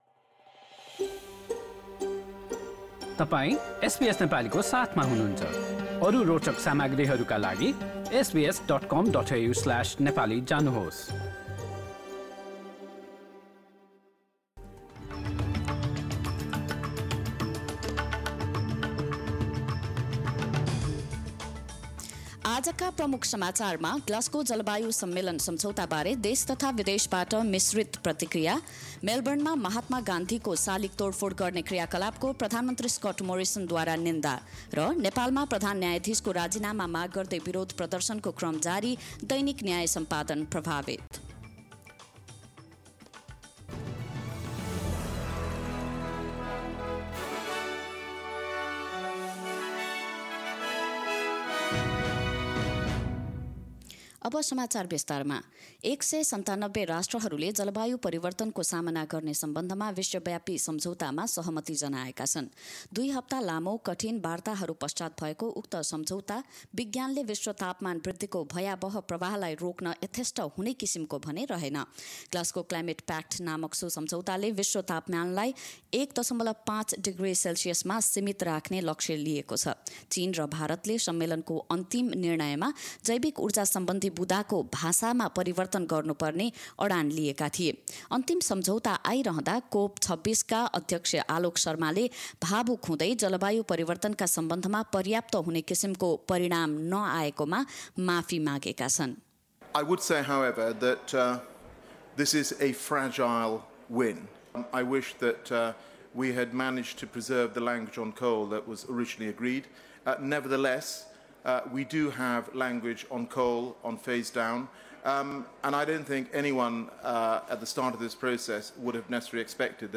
Listen to the latest news updates in Nepali language.